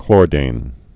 (klôrdān) also chlor·dan (-dăn)